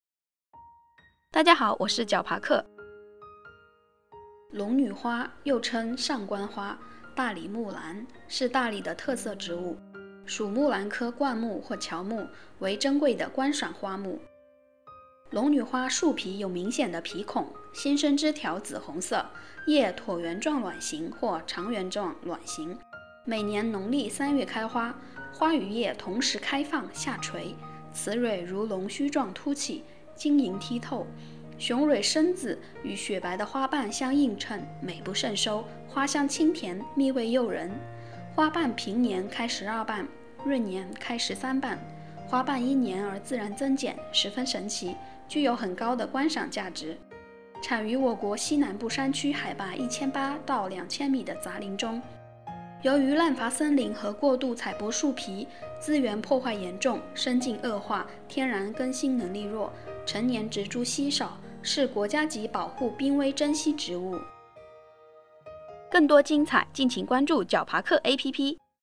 龙女花----- 手机用户 解说词: 龙女花又称上关花，大理木兰，是大理的特色植物。